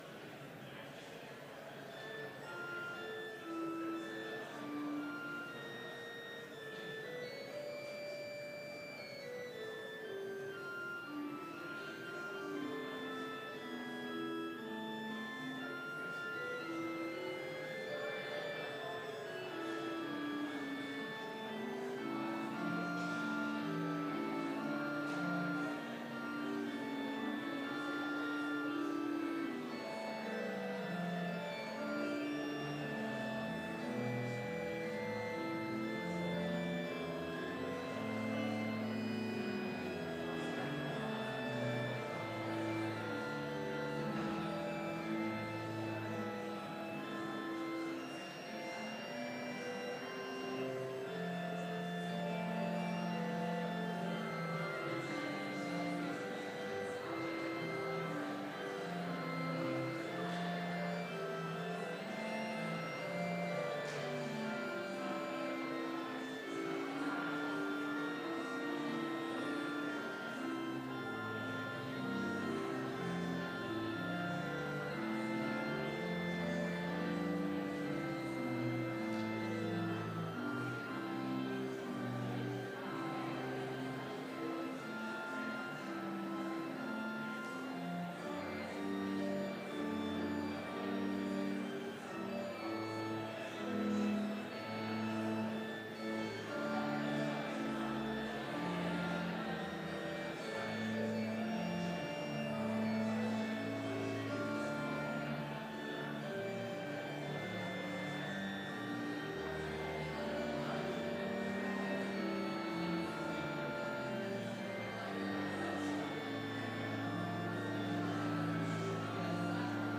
Complete service audio for Chapel - September 5, 2019